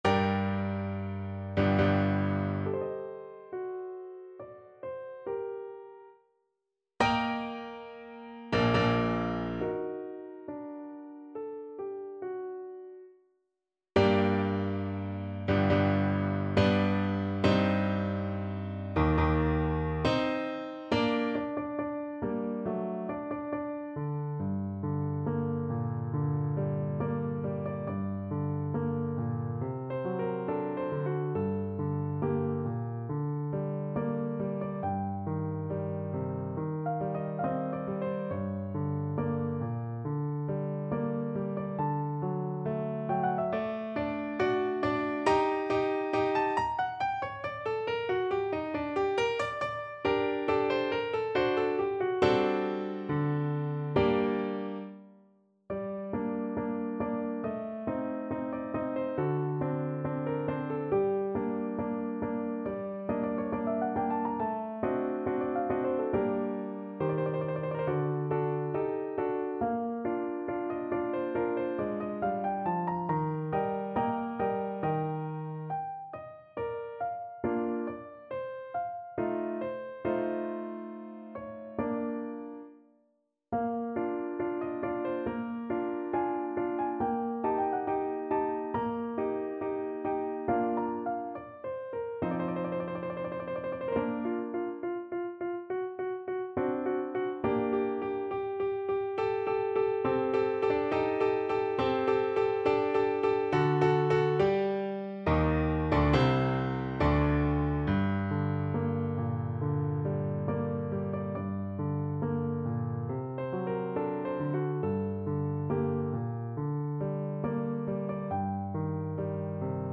Piano version
No parts available for this pieces as it is for solo piano.
4/4 (View more 4/4 Music)
=69 Andante cantabile
Piano  (View more Advanced Piano Music)